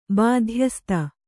♪ bādhyasta